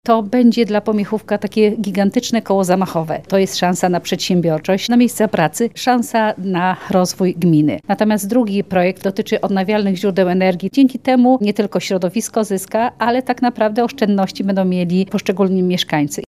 – Oba projekty pomogą w rozwoju gmin i przyniosą wymierne korzyści mieszkańcom – mówi członek zarządu województwa mazowieckiego Elżbieta Lanc.